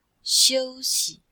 Cours-de-chinois-S2-L2-xiu1xi5.mp3